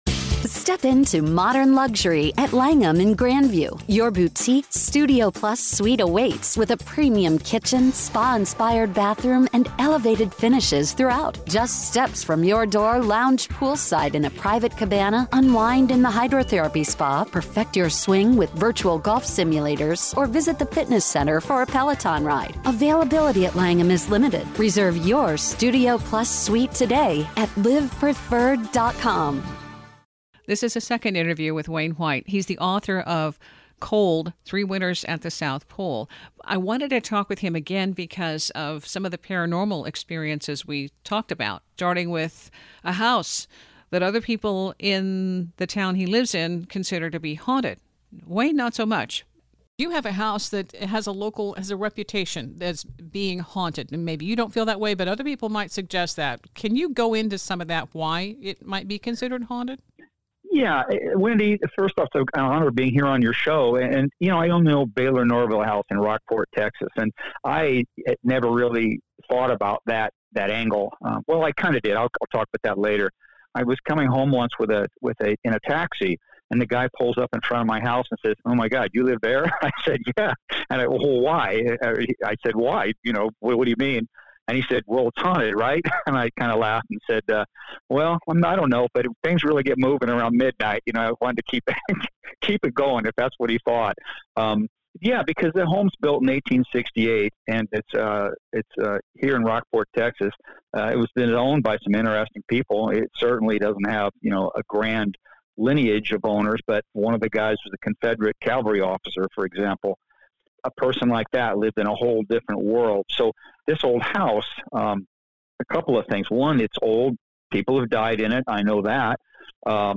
~ The house referred to in this interview is the Baylor-Norvell house of Rockport, Texas.